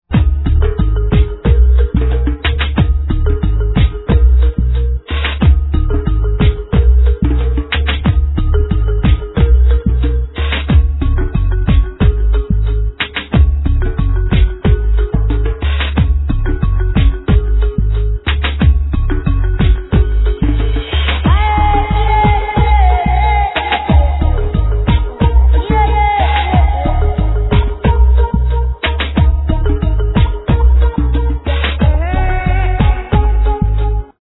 World Music
sung by a little "Bushman" boy